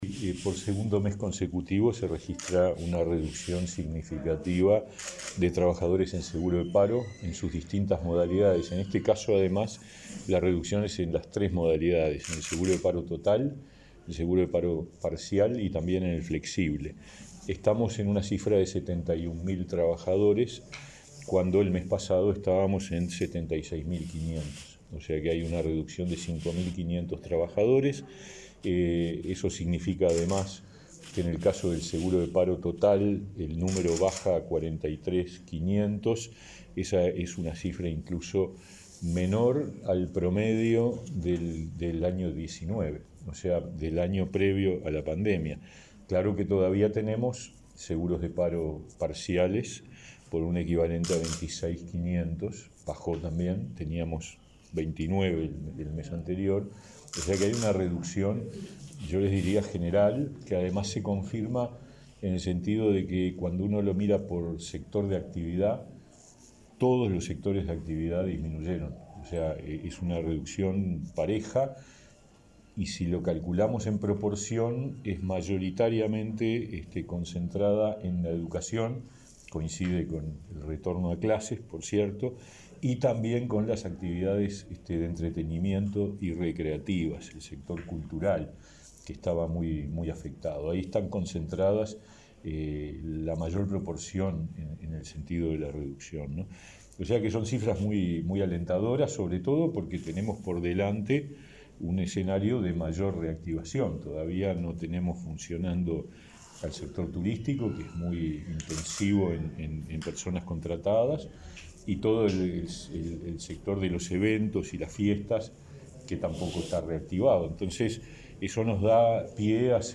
Declaraciones de prensa del ministro de Trabajo, Pablo Mieres